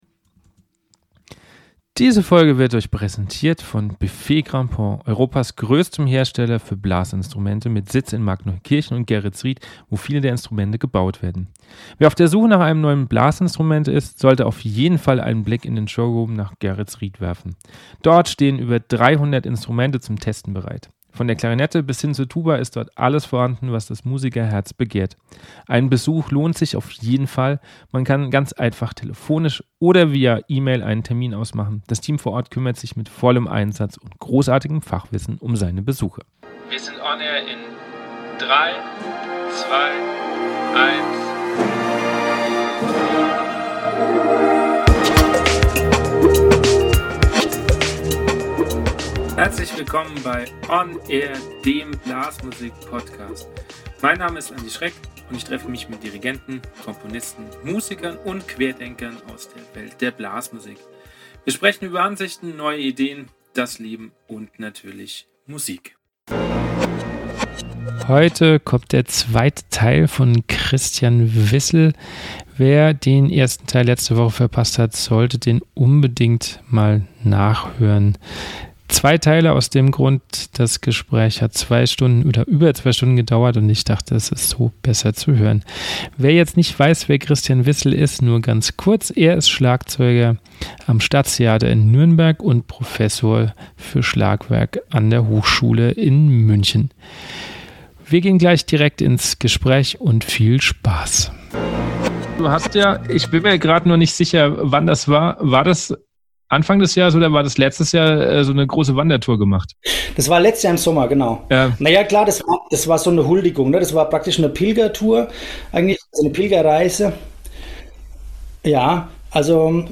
Hier gibt es nun den zweiten Teil des Gesprächs